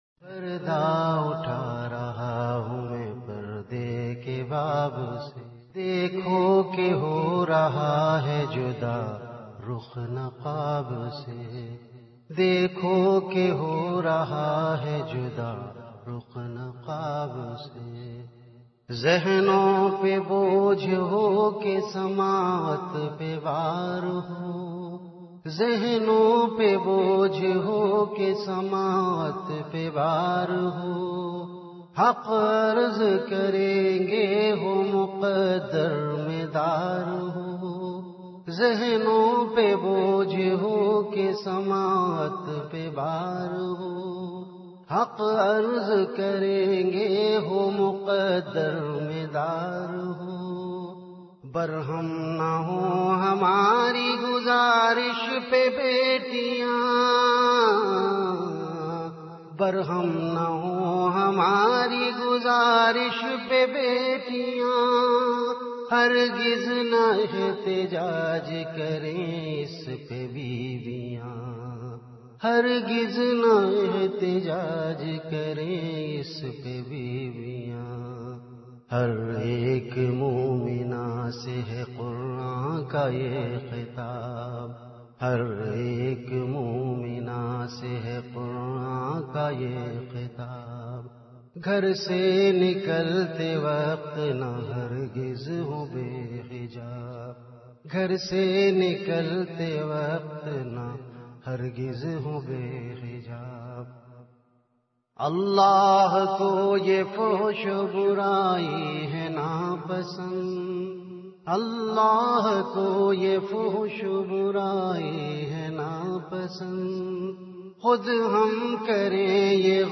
Delivered at Home.
Category Ashaar
Venue Home Event / Time After Isha Prayer